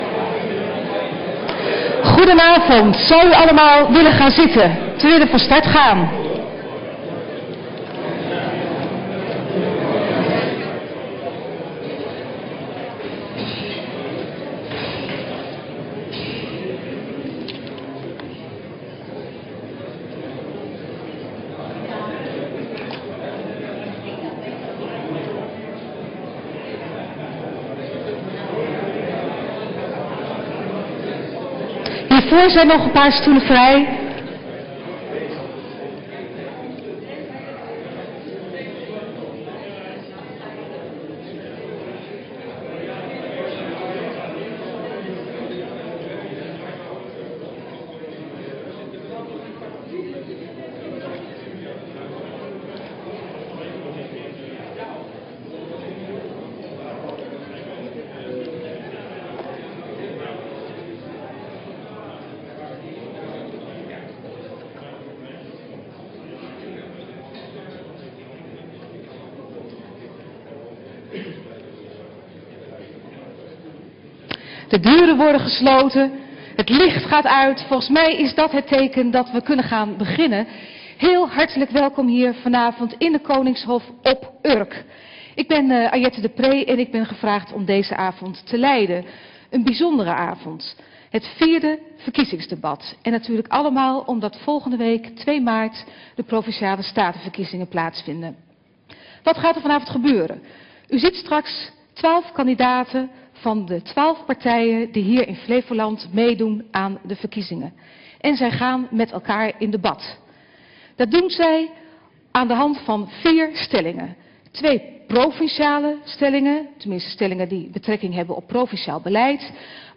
Verkiezingsdebat
Locatie: Statenzaal